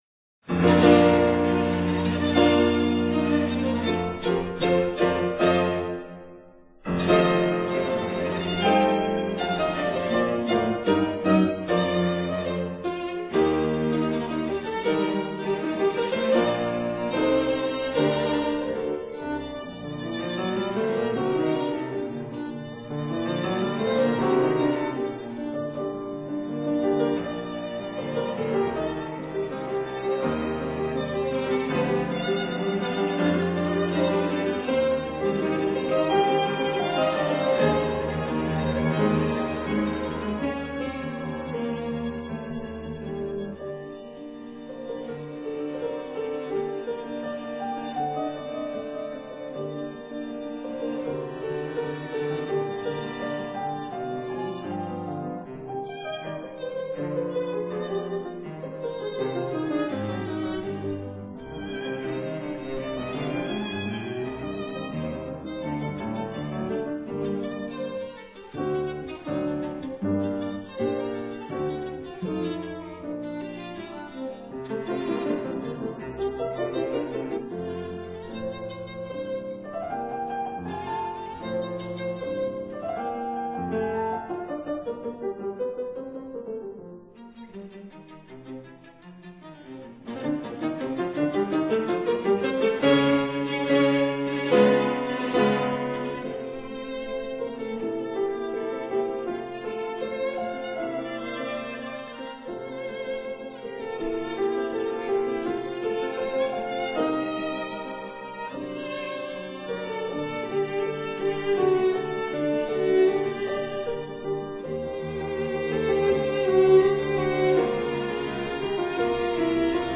violin
cello
piano Date